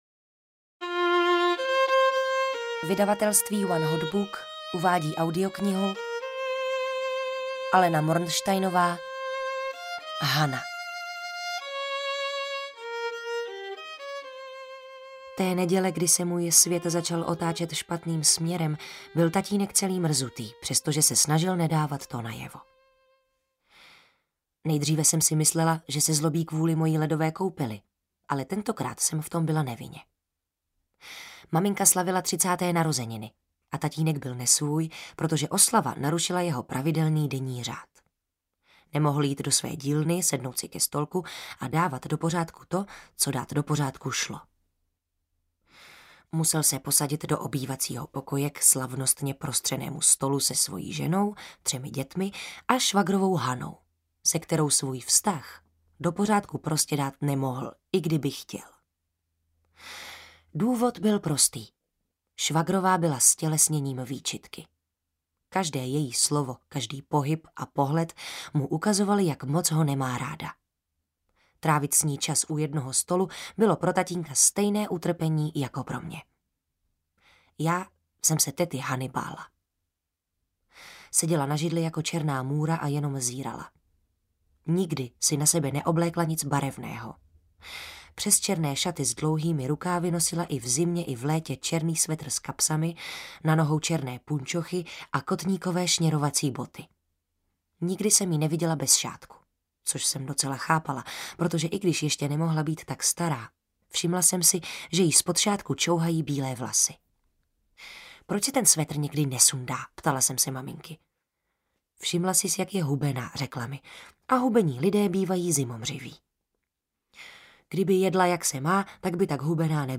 Hana audiokniha
Ukázka z knihy
• InterpretTereza Dočkalová, Lenka Vlasáková